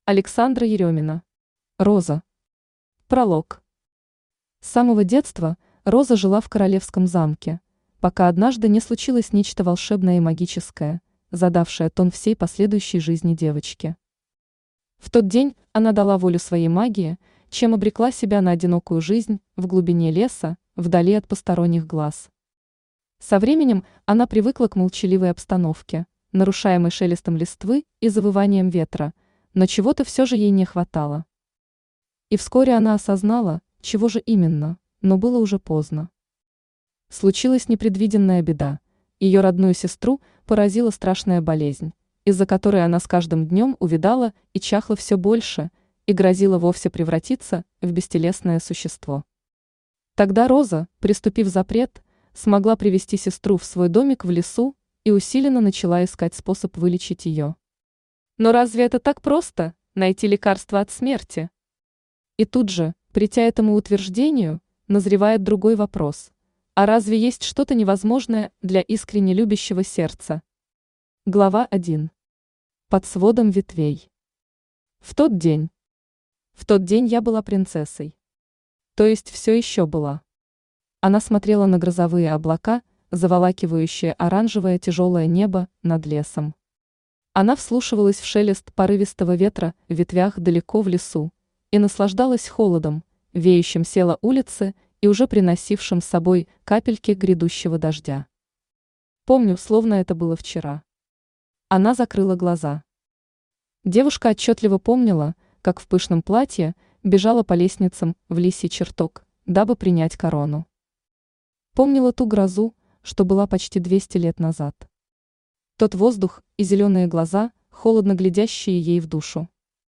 Aудиокнига Роза Автор Александра Ерёмина Читает аудиокнигу Авточтец ЛитРес.